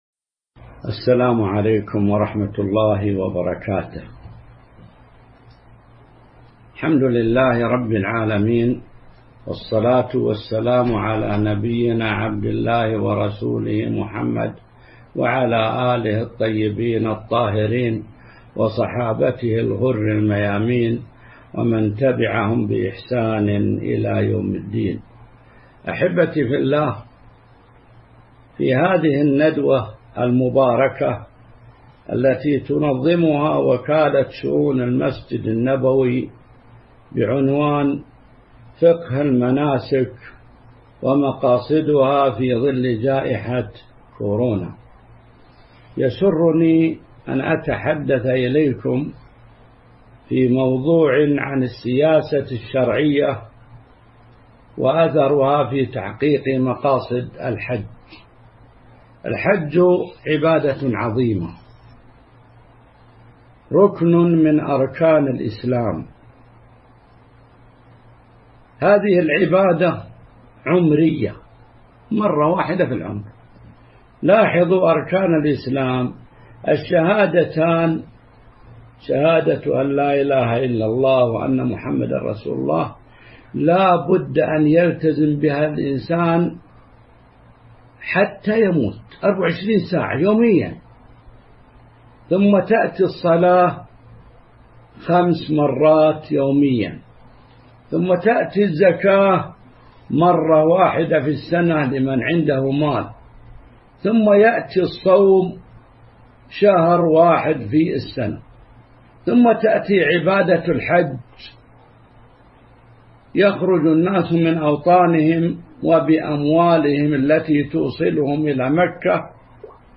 تاريخ النشر ١ ذو الحجة ١٤٤١ هـ المكان: المسجد النبوي الشيخ